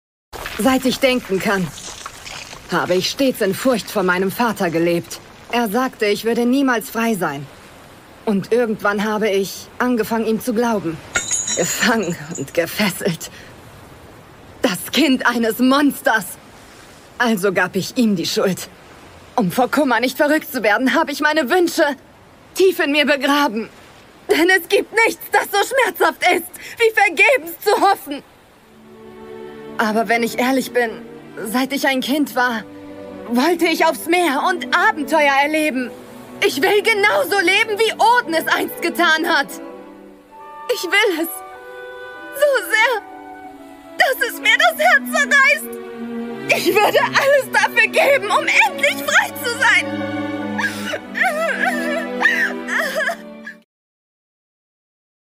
Sprachproben
Sprecherin, Synchronsprecherin, Schauspielerin, Regisseurin